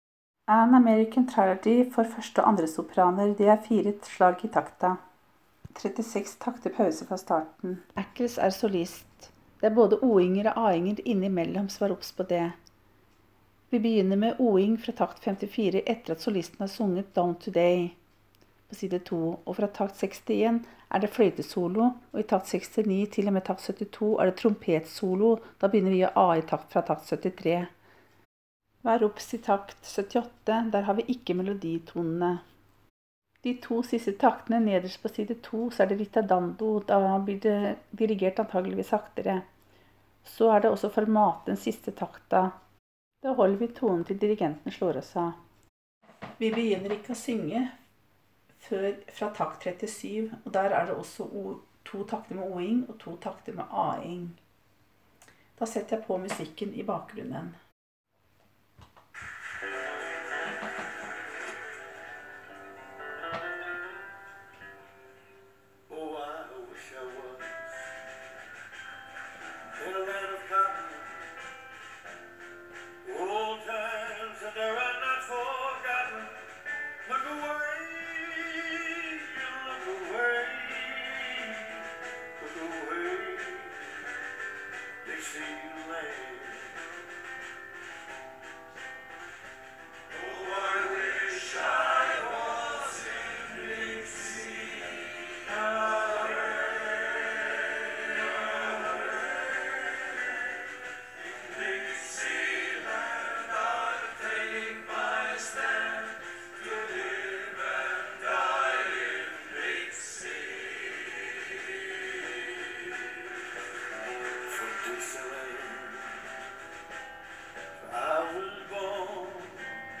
1.og2.Sopran: ny fil 13.8.19 An american trilogy: